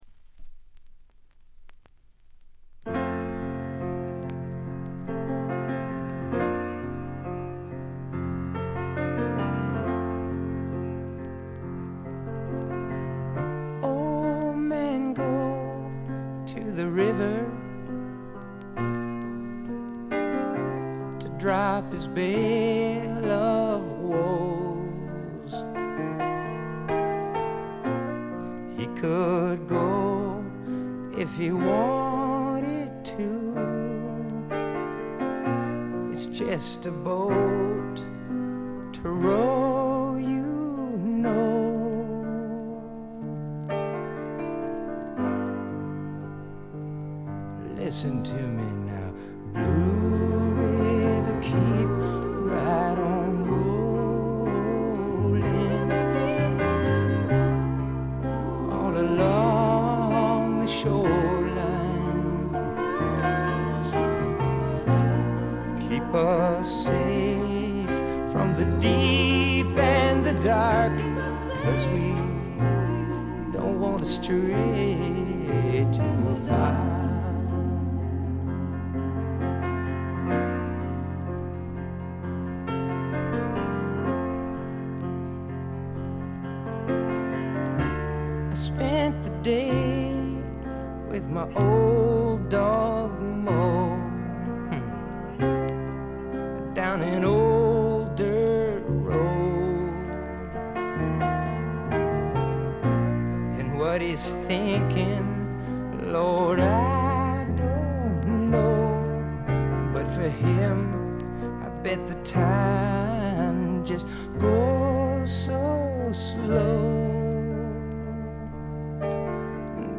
部分試聴ですが、軽いチリプチ、散発的なプツ音少し。
控えめな演奏をバックに複雑な心象風景を淡々と描いていく歌声が本作の最大の魅力でしょう。
試聴曲は現品からの取り込み音源です。